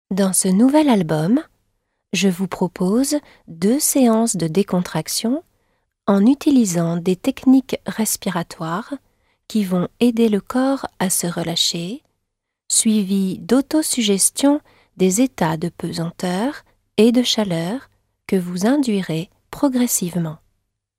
Deux séances de relaxation sophrologique vous sont proposées. La première vous emmène dans un décor de neige, dans la blancheur des montagnes et le bruit des sources fraîches qui vous régénéreront.
Vous accéderez à un état de détente particulièrement réparateur grâce au mélange harmonieux d’une voix qui vous guide pas à pas et de temps musicaux propices au relâchement.